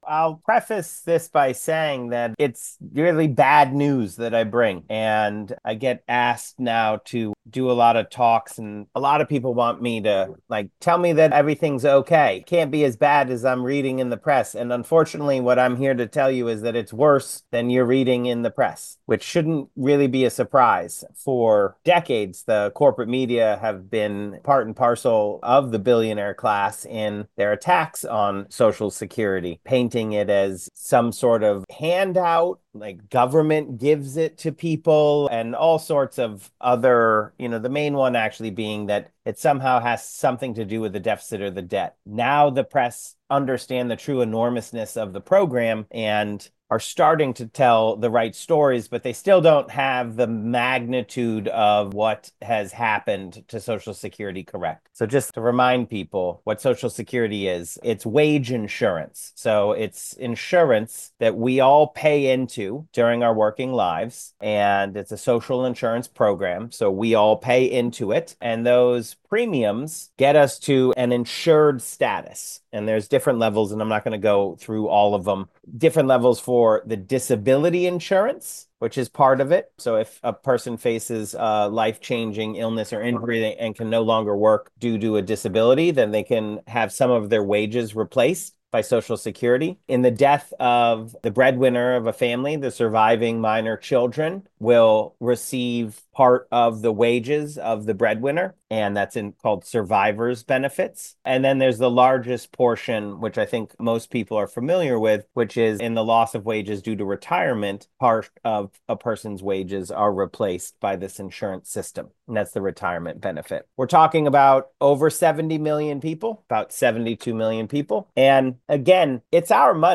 Briefing from Social Security Works